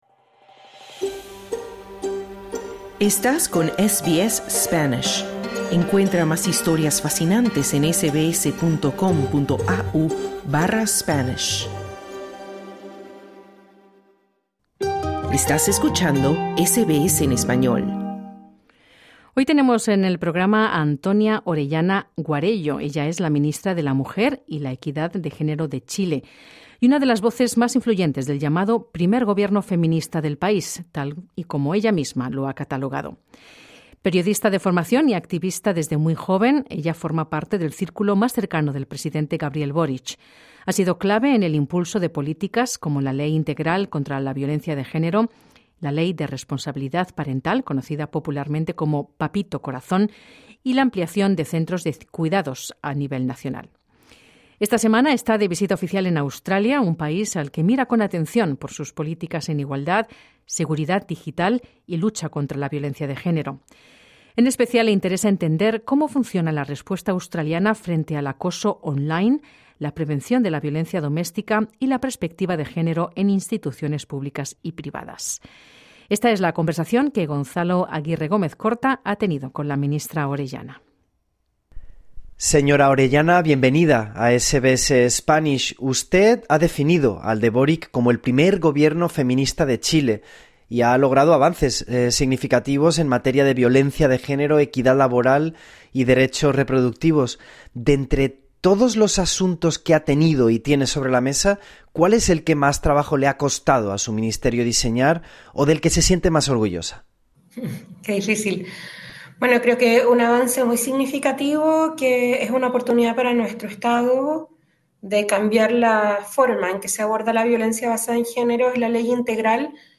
La ministra de la Mujer y la Equidad de Género en Chile, Antonia Orellana, está en Australia para, entre otros asuntos, entender de primera mano las políticas de seguridad digital y cómo se combate la violencia de género en línea. En esta entrevista exclusiva con SBS Spanish, reconoce que observan el modelo australiano para implementarlo en Chile.